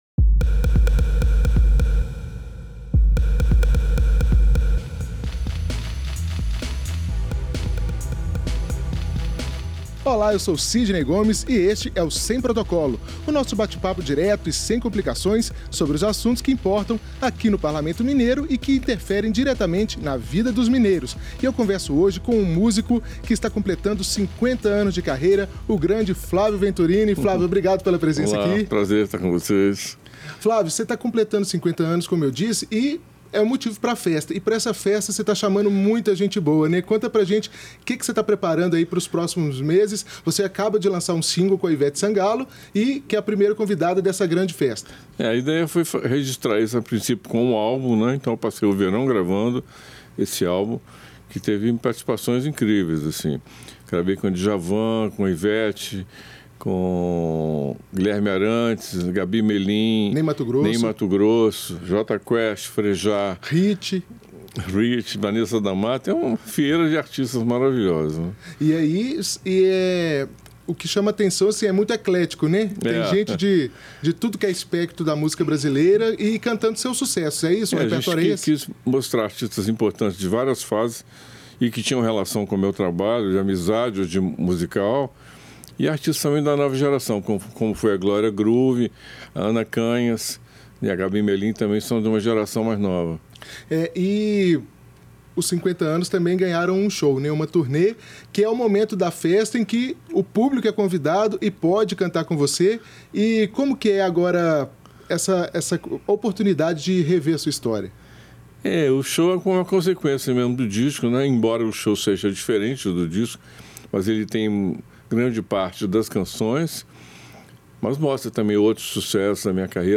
Neste programa, Flávio Venturini recorda o início da carreira, com as primeiras aulas de acordeon e piano, o reconhecimento maior com a gravação da música "Nascente" por Milton Nascimento e o Clube da Esquina, e os rumos da música na era dos streamings.